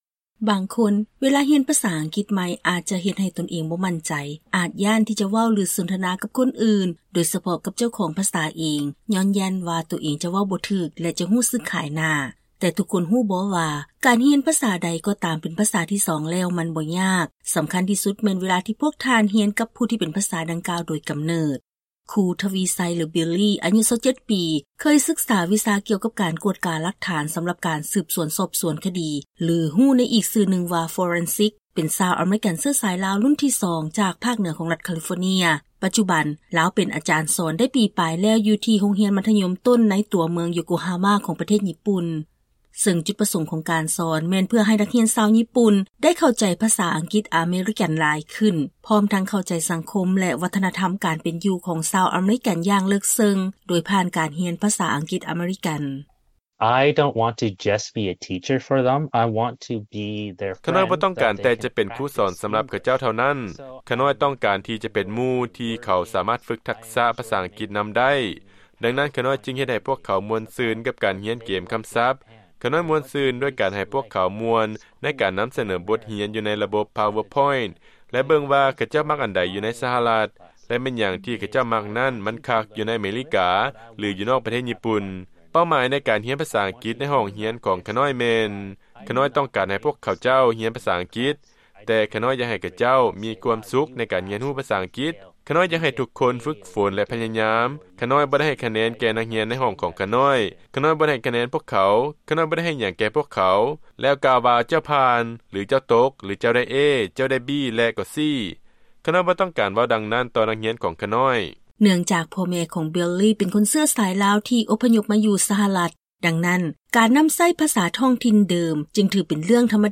ມື້ນີ້ ພວກເຮົາມີລາຍງານກ່ຽວກັບປະສົບການການສອນພາສາອັງກິດຈາກຄູສອນພາສາ ທີ່ກໍາລັງສອນພາສາອັງກິດຢູ່ໃນນະຄອນ ໂຢໂກຮາມາ.